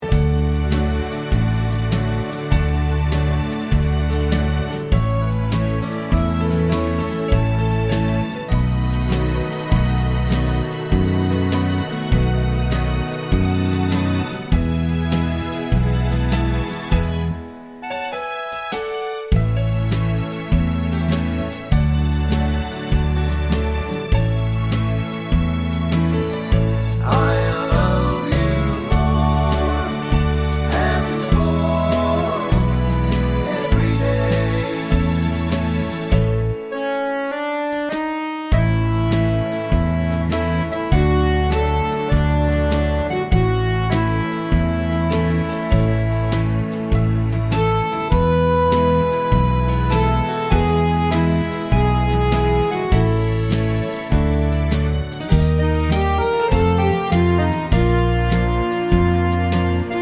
no Backing Vocals Irish 3:01 Buy £1.50